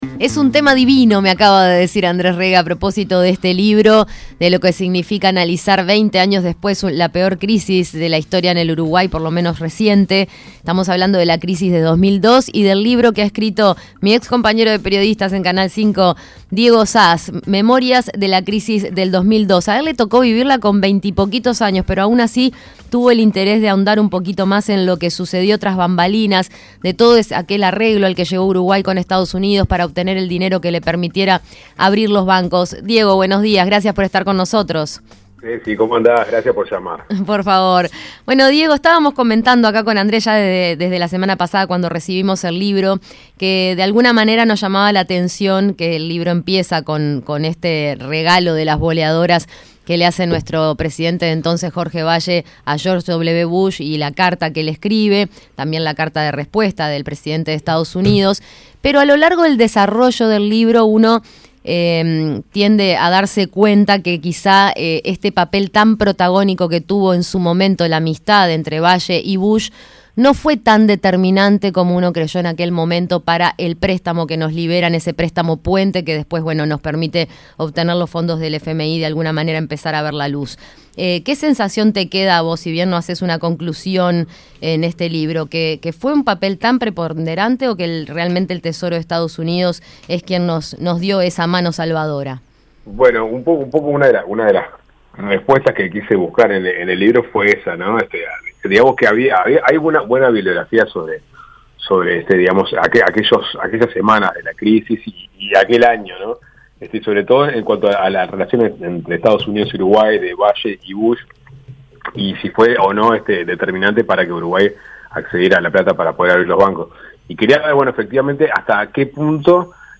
Escuche la columna completa